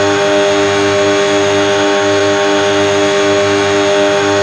rr3-assets/files/.depot/audio/sfx/electric/mp4x_on_high_12000rpm.wav